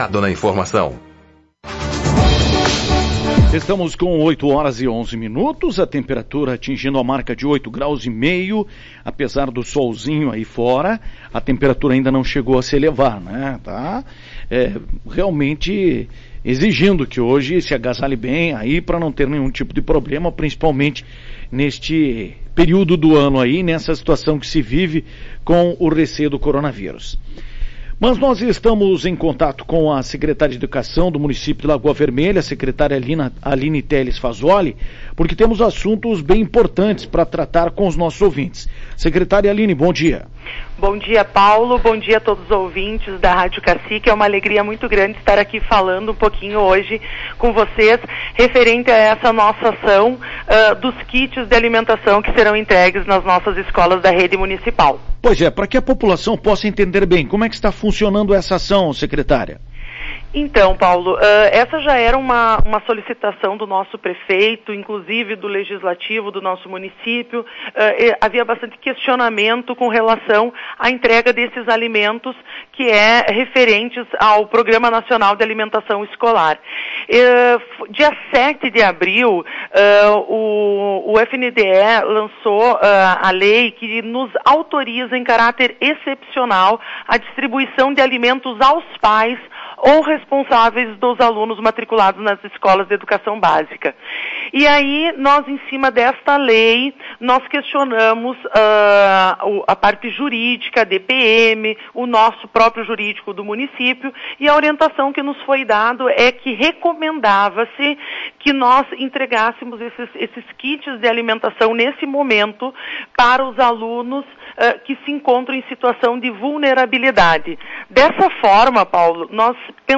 Ouça a entrevista com a secretária de educação Aline Teles Fasoli